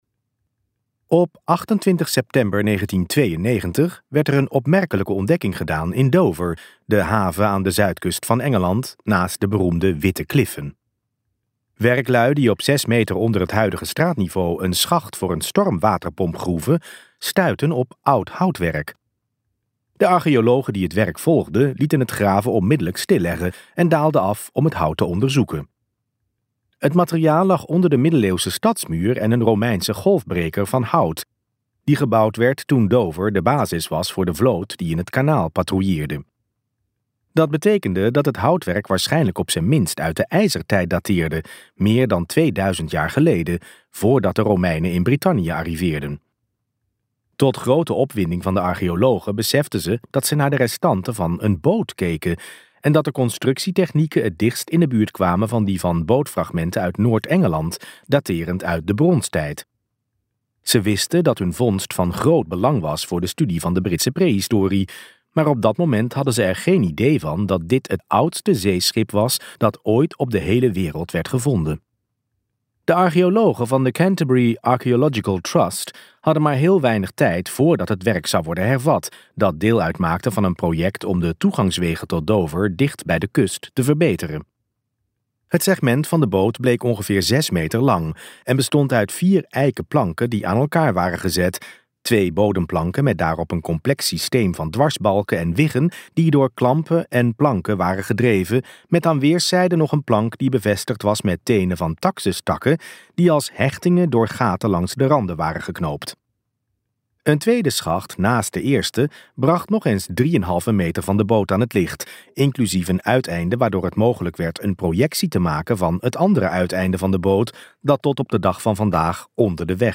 Uitgeverij Omniboek | Een geschiedenis van de wereld in twaalf scheepswrakken luisterboek